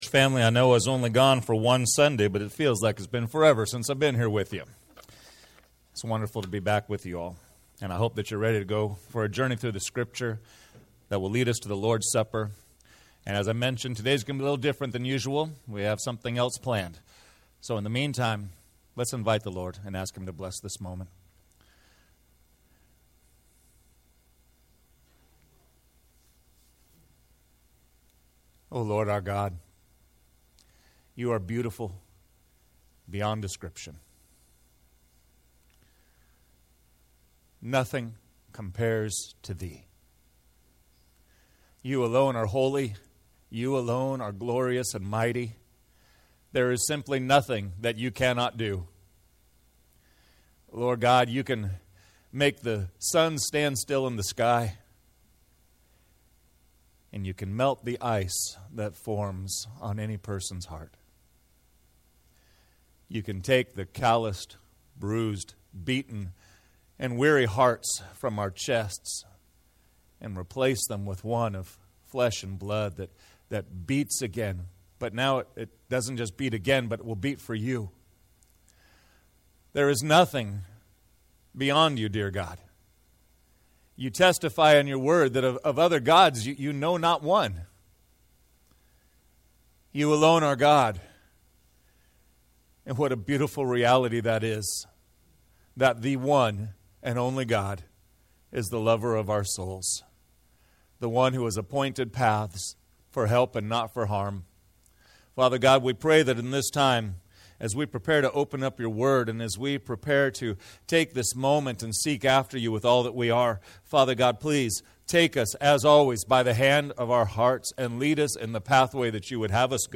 Sermons | First Baptist Church Solvang